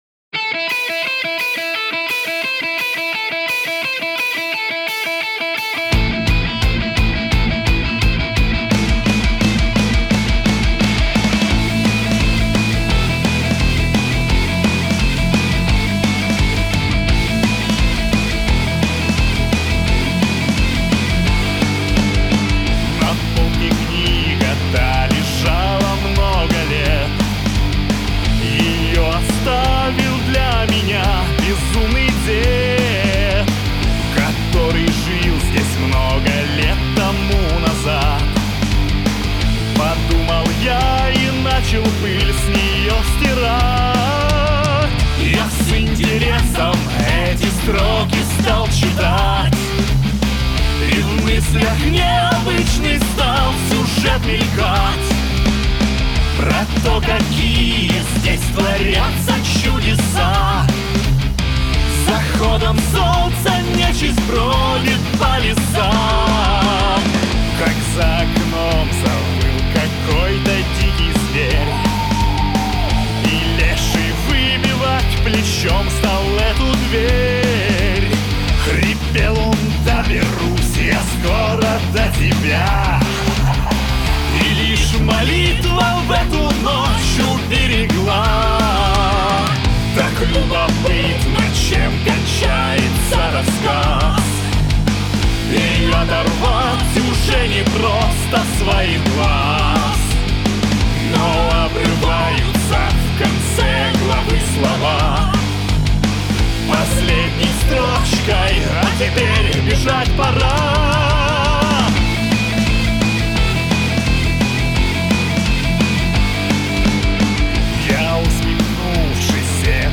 • Категория:Русский метал